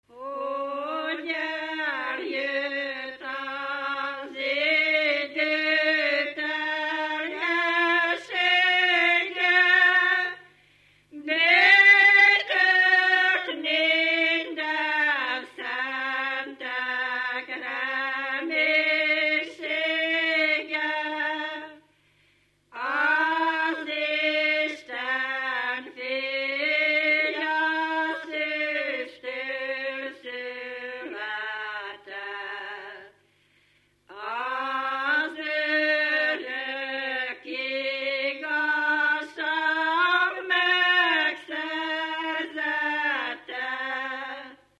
Dunántúl - Verőce vm. - Haraszti
Stílus: 7. Régies kisambitusú dallamok
Kadencia: 4 (2) V 1